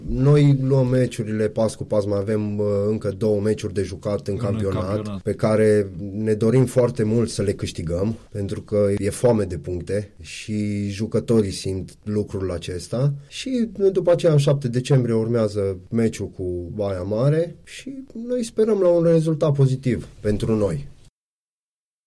la Unirea FM